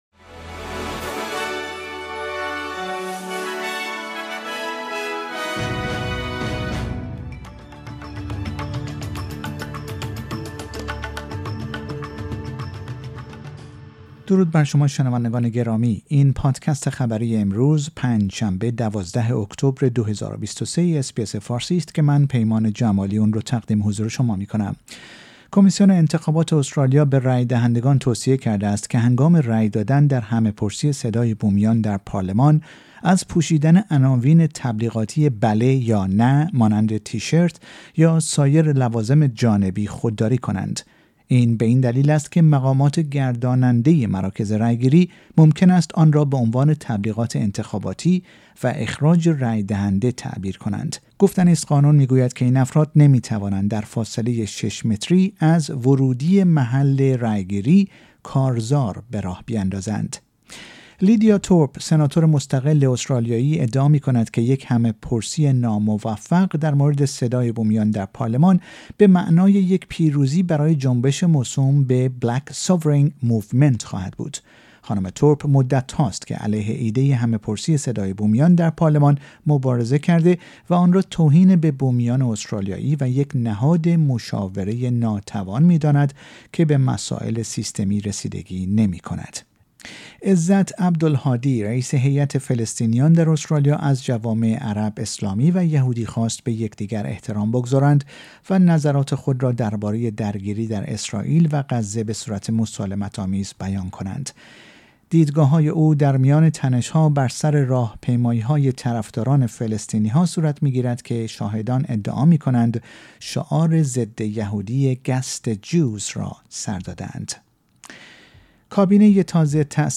در این پادکست خبری مهمترین اخبار استرالیا و جهان در روز پنج شنبه ۱۲ اکتبر، ۲۰۲۳ ارائه شده است.